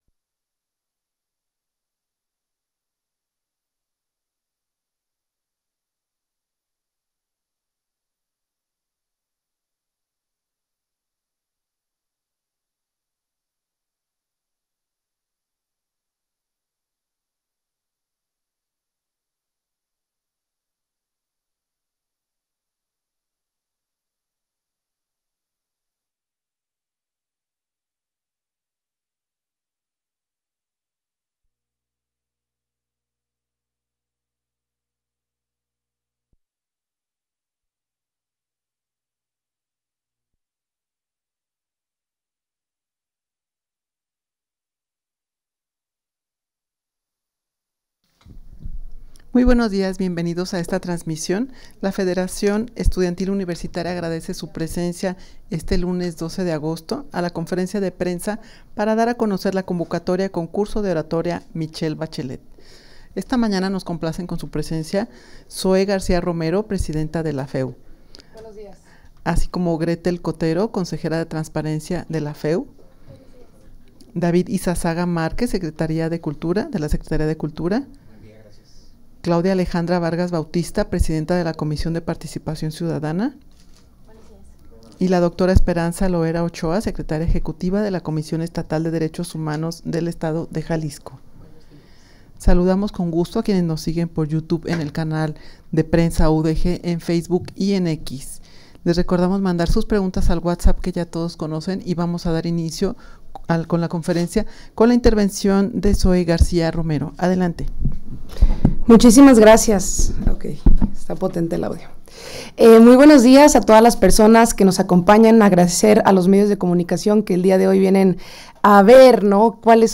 Audio de la Rueda de Prensa
rueda-de-prensa-convocatoria-concurso-de-oratoria-michelle-bachelet.mp3